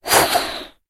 Звуки бумеранга - скачать и слушать онлайн бесплатно в mp3